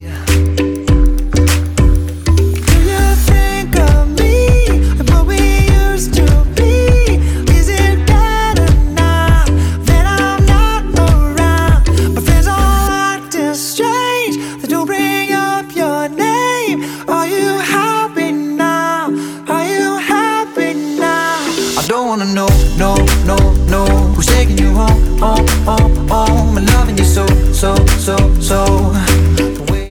• Pop
American pop rock band